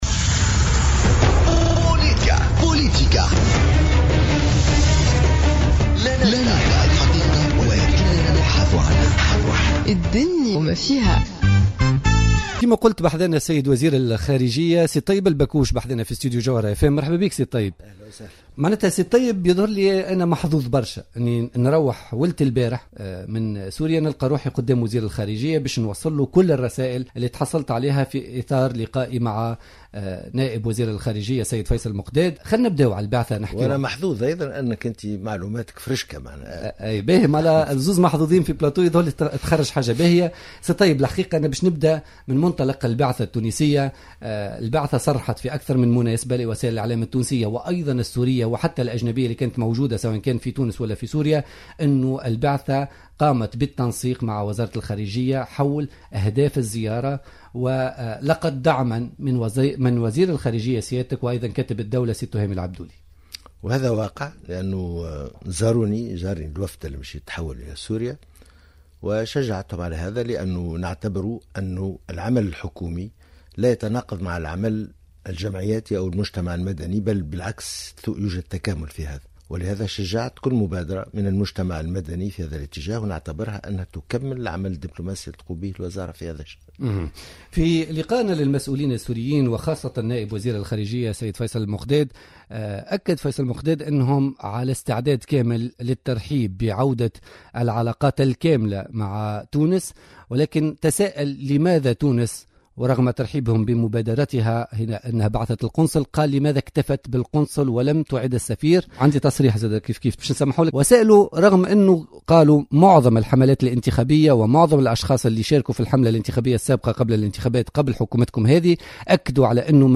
حوار حصري مع الطيب البكوش وزير الخارجية لبرنامج بوليتيكا